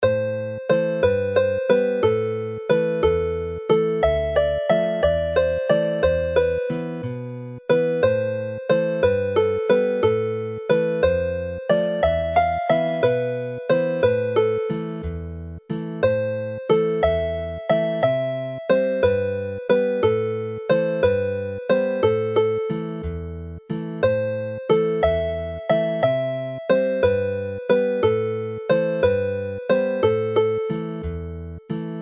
Jig version
a livelier variation of the jig
Play the melody slowly